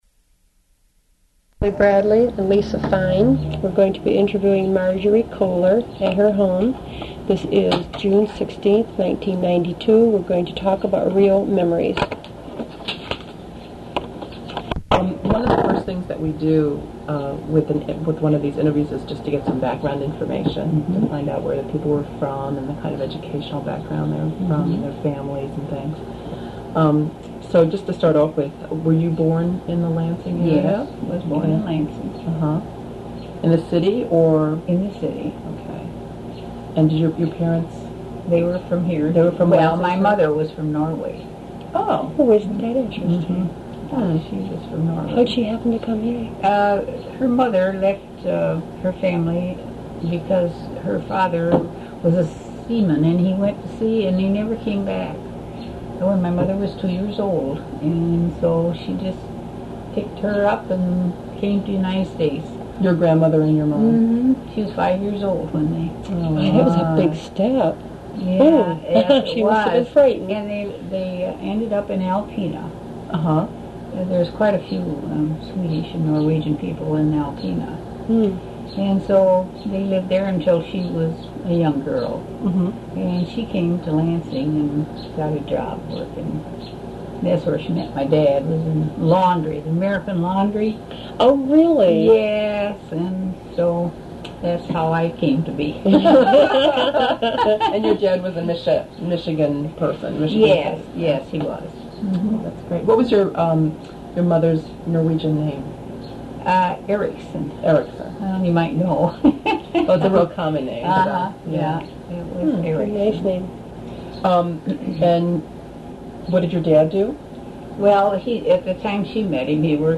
Recorded as part of REO Memories oral history project.